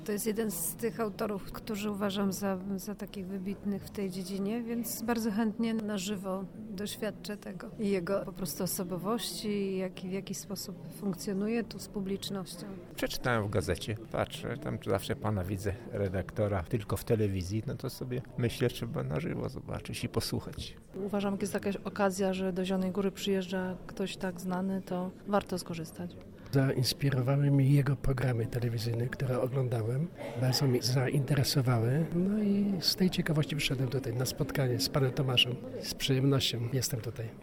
Sala im. Janusza Koniusza wypełniona po brzegi.